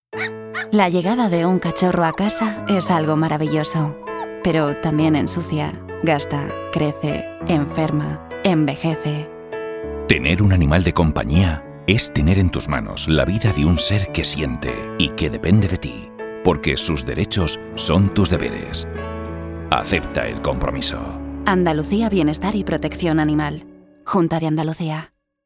(mp4) Cuñas de radio Animales de compañia.